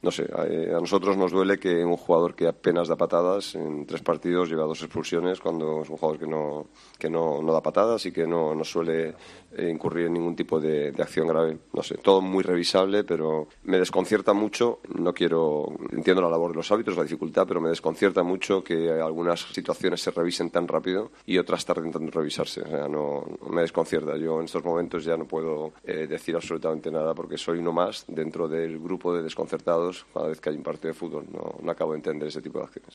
Antes, en rueda de prensa, el técnico Quique Sánchez Flores fue algo más mesurado, aunque se mostró igualmente extrañado por cómo funciona el VAR: "A nosotros nos duele que un jugador que apenas da patadas lleve dos expulsiones en tres partidos.